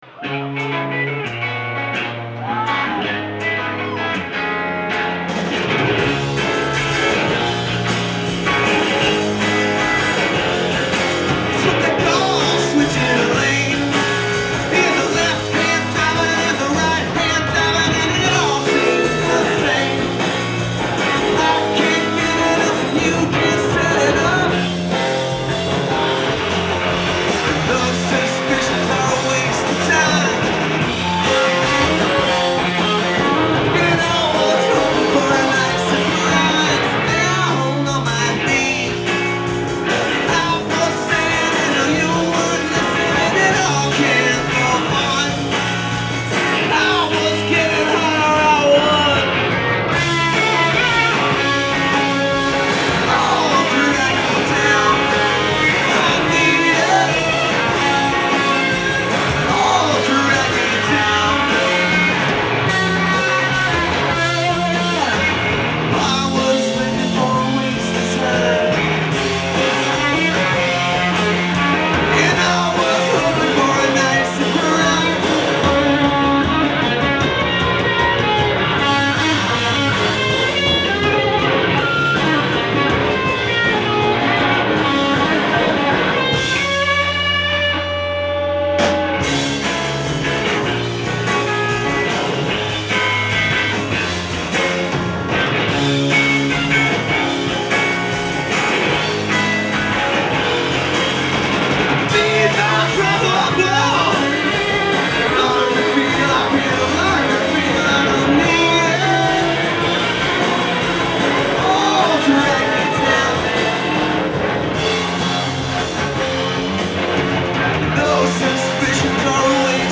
Live at the Paradise
in Boston, Massachusetts